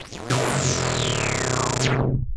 Glitch FX 02.wav